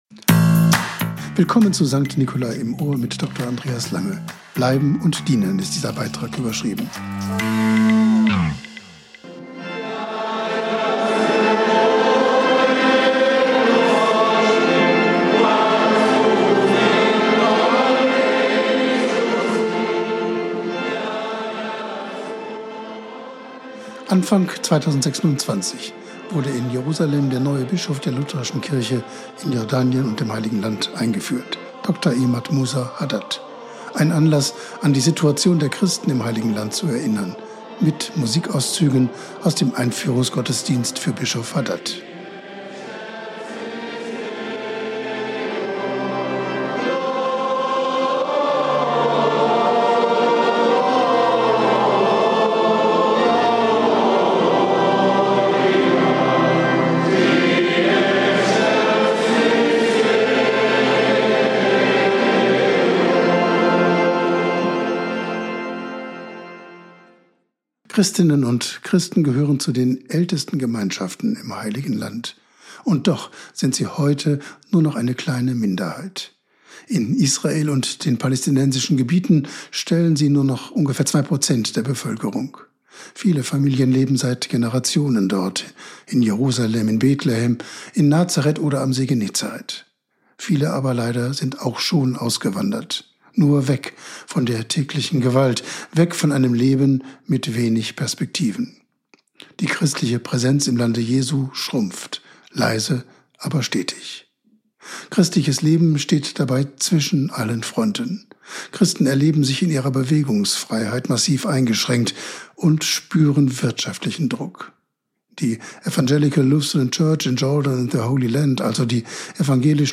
Wort und Musik aus der St. Nicolai-Kirche Lemgo